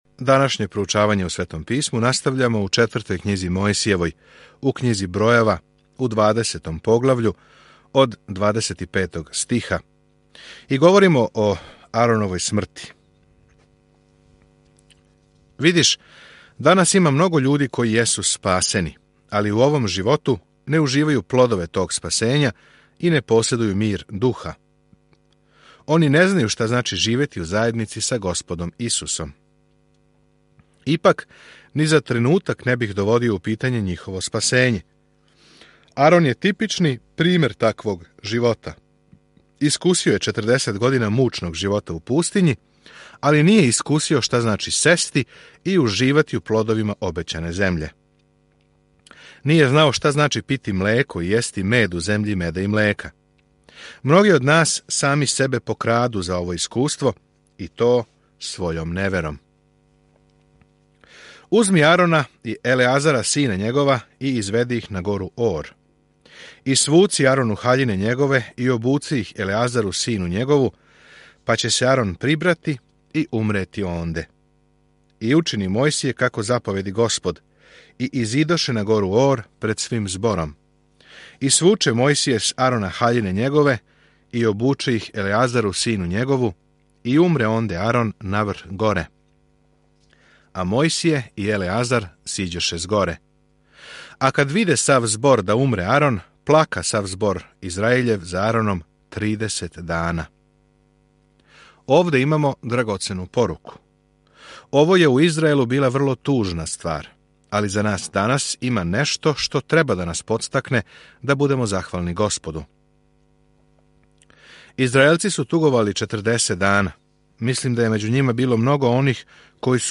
Mojsijeva 22:1-13 Dan 11 Započni ovaj plan Dan 13 O ovom planu У Књизи Бројева, ми ходамо, лутамо и обожавамо се са Израелом током 40 година у пустињи. Свакодневно путујте кроз Бројеве док слушате аудио студију и читате одабране стихове из Божје речи.